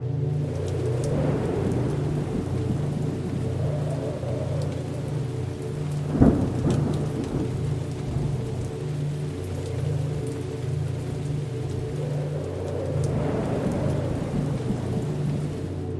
سمپل فضاسازی پد Aurora
تعداد: 24 آکورد مینور و ماژور
13 تک نت جهت ساخت انواع آکوردها
از پکیج های سری امبینت
• دکلمه: فراهم کردن پس‌زمینه‌ای آرام و دلنشین برای دکلمه‌ها